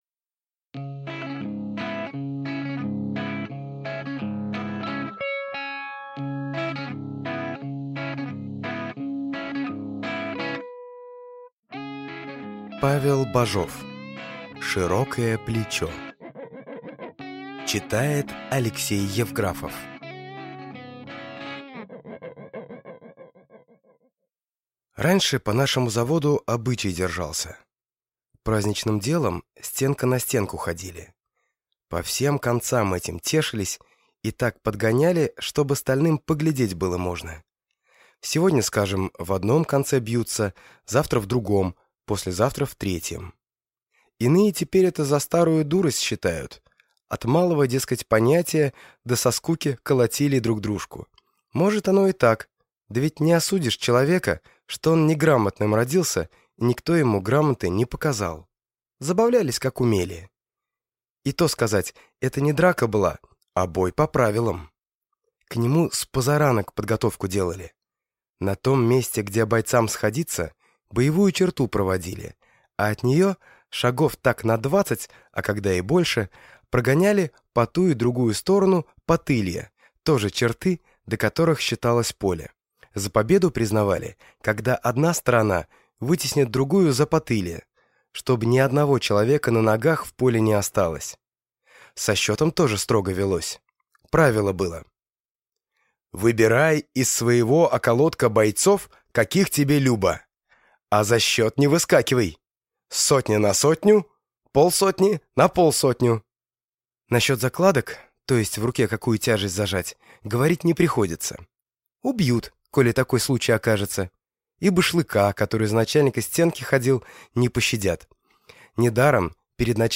Аудиокнига Широкое плечо | Библиотека аудиокниг